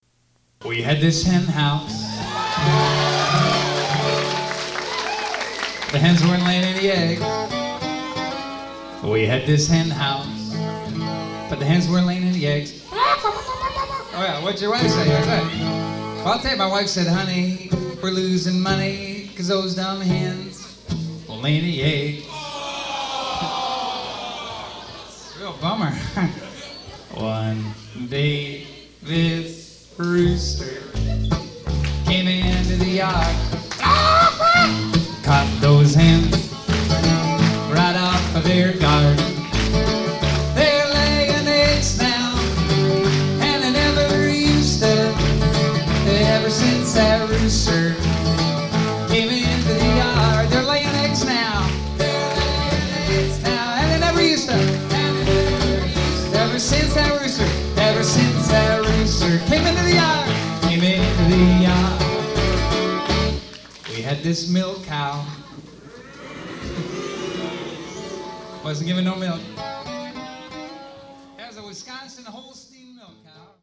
You can here the audience do some special parts.